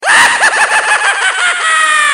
evillaugh01.wav